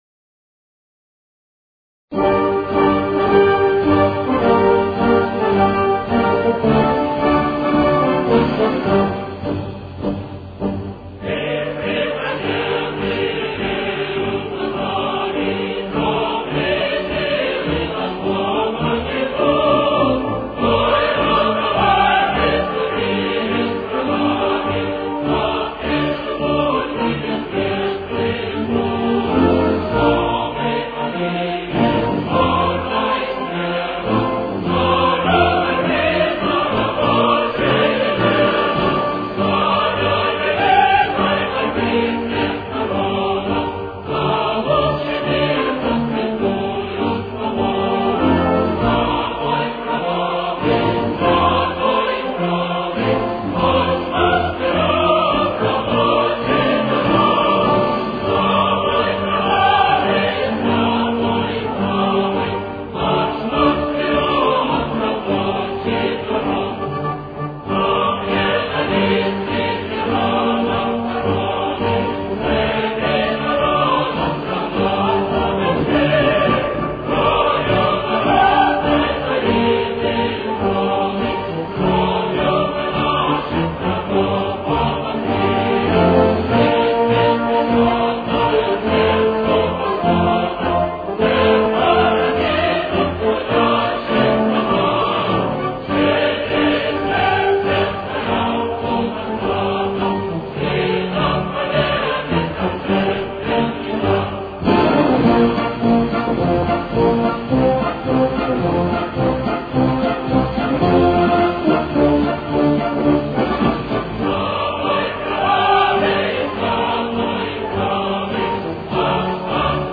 с очень низким качеством (16 – 32 кБит/с)
Темп: 104.